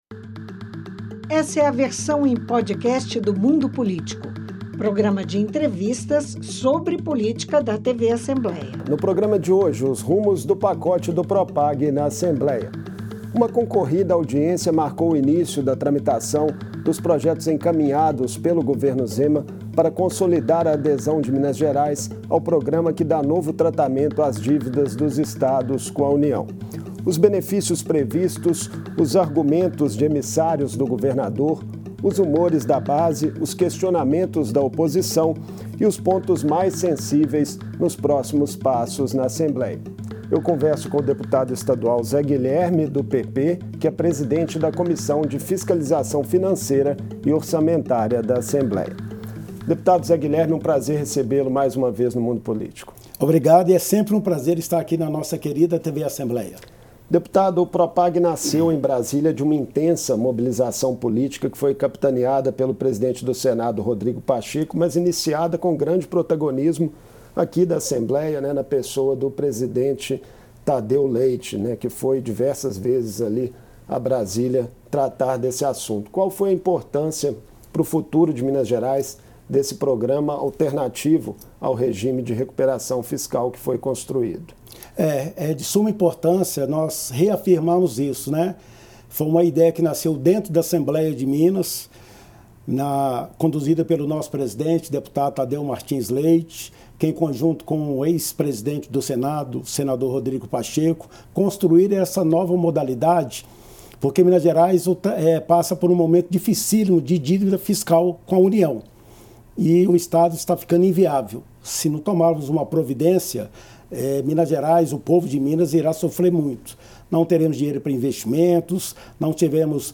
O deputado Zé Guilherme (PP), presidente da comissão de Fiscalização Financeira e Orçamentária falou ao Mundo Político sobre os 13 projetos que estão no legislativo e da necessidade do parlamento ser ágil na análise das proposições. Para ele, o Propag é a melhor saída para a dívida do Estado. O deputado falou também sobre as fusões de partidos políticos e as projeções para as eleições de 2026.